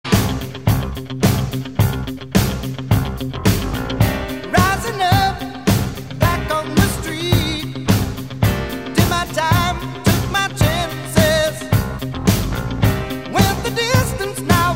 • Category Pop